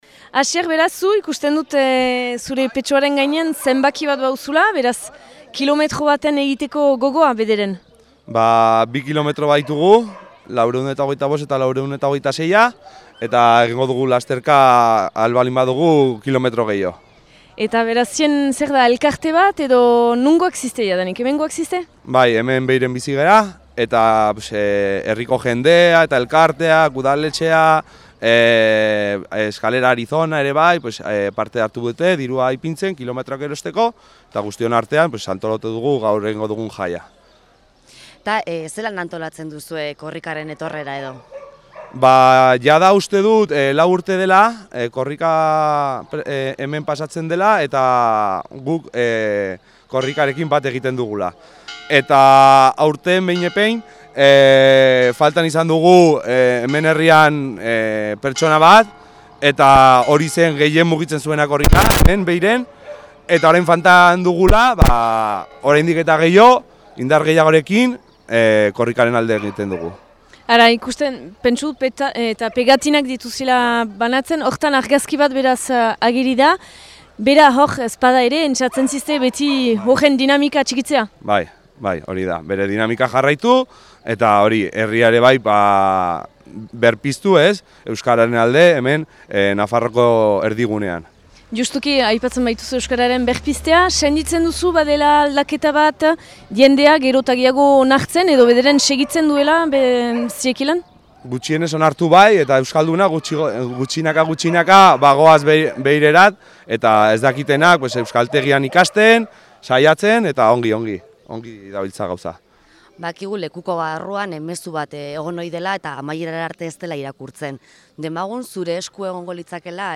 herriko gazte batekin mintzatu ginen